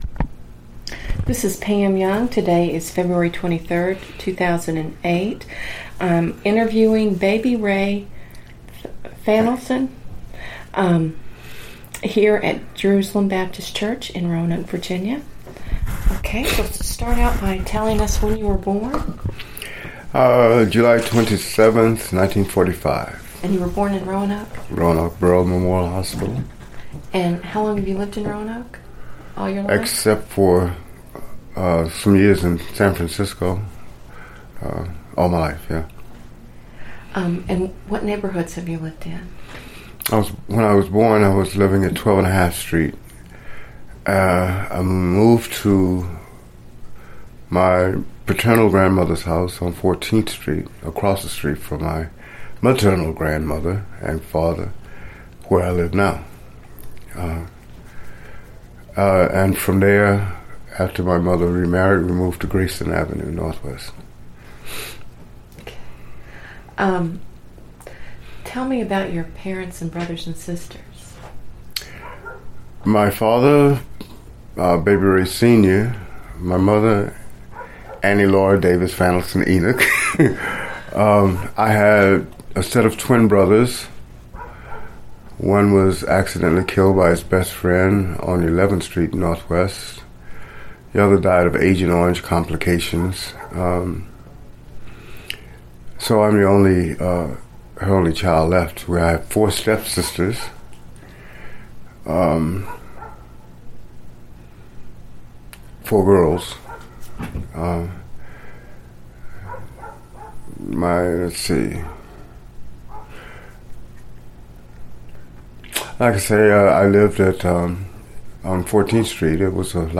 Neighborhood History Interview
Location: Jerusalem Baptist Church